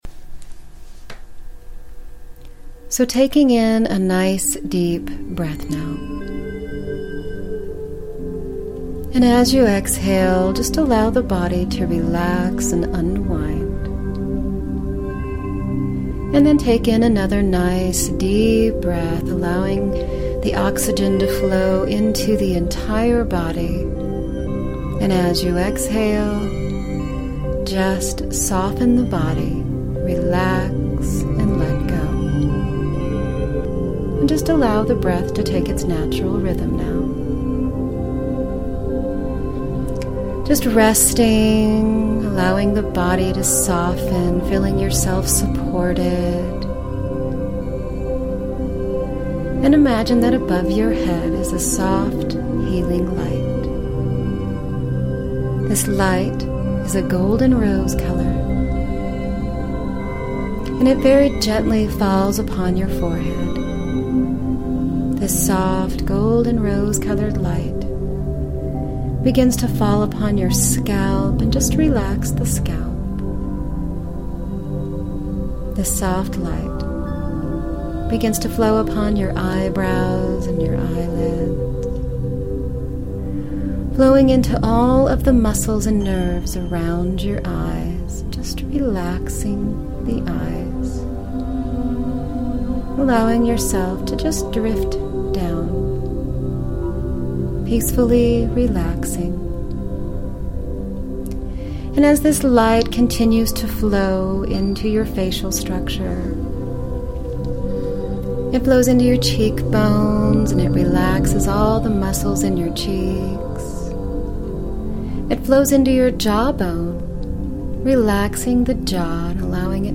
Guided Meditation with hypnosis to open your spirit to allow and receive prosperity and abundance. money, security, safety, freedom, easy, flow.